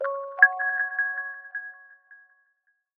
çoğunlukla eğlenceli ve hareketli zil seslerine sahip.